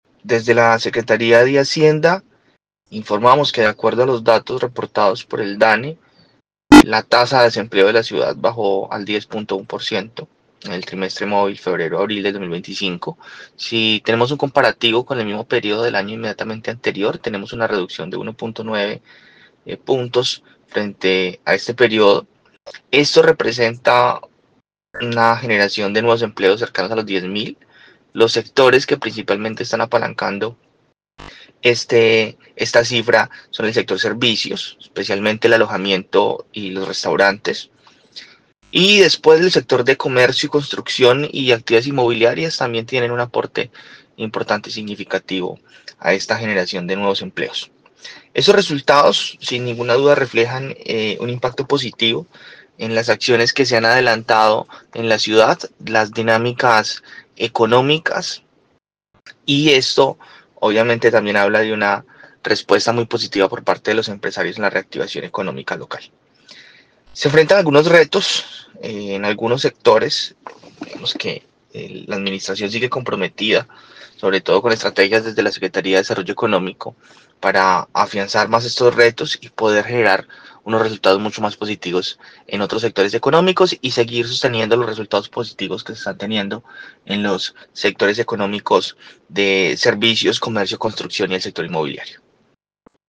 Yeison Pérez, secretario de hacienda de Armenia